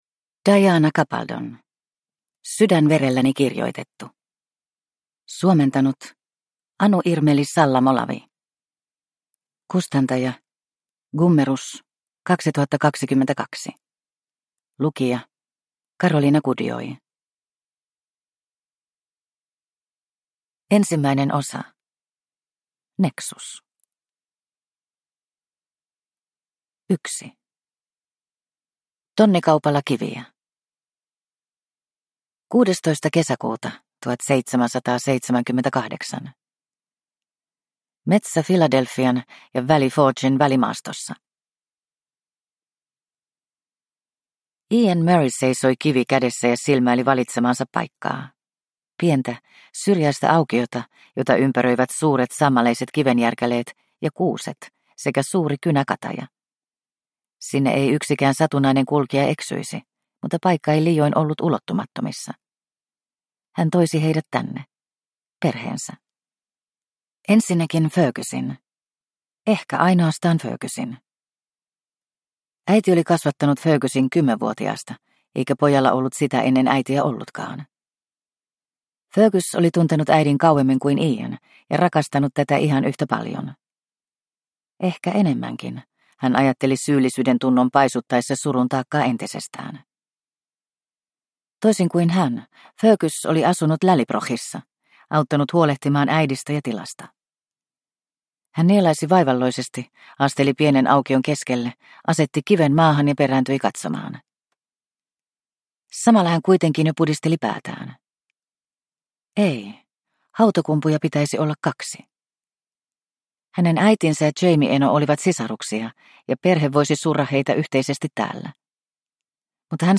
Sydänverelläni kirjoitettu – Ljudbok – Laddas ner